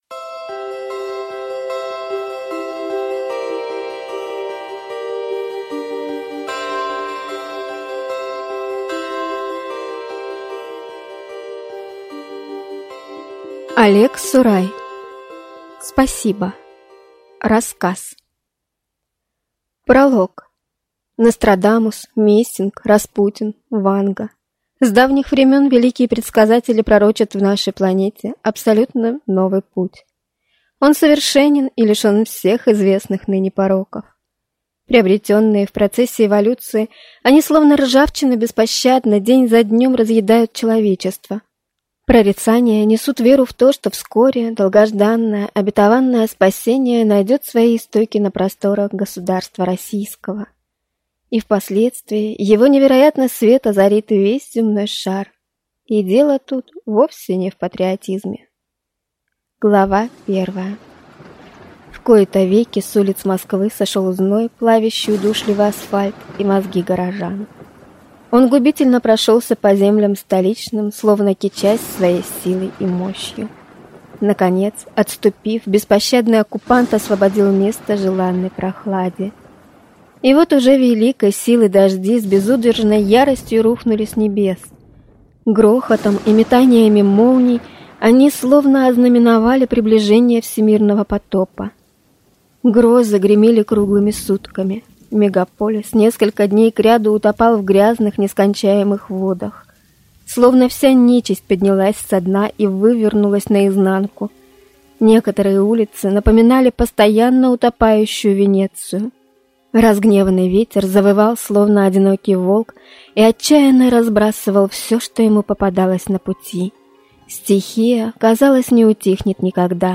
Аудиокнига Спасибо | Библиотека аудиокниг